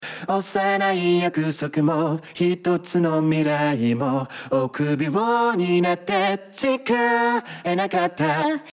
メインボーカルのユニゾン（コーラス）を簡単に作成
▼ReSingの男性ボーカルで作成したユニゾン
このように、メインボーカルからピッチ補正ツールでピッチを大きく変えると不自然な音質になりますが、ReSingで変換することで機械的なニュアンスも自然な歌声になります。